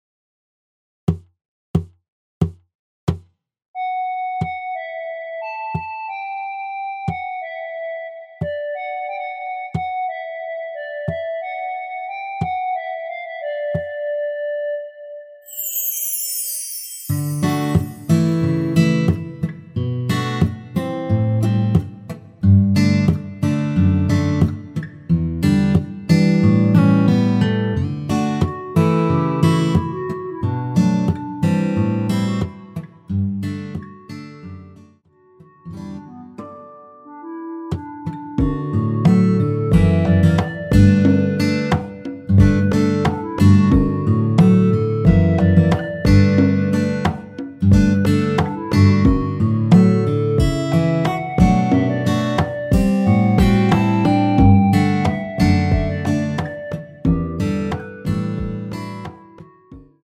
전주 없이 무반주로 노래가 시작 하는 곡이라서
노래 들어가기 쉽게 전주 1마디 넣었으며
노래 시작 앞부분이 무반주라서 기타 바디 어택으로
원키에서(-2)내린 멜로디 포함된 MR입니다.
앞부분30초, 뒷부분30초씩 편집해서 올려 드리고 있습니다.
중간에 음이 끈어지고 다시 나오는 이유는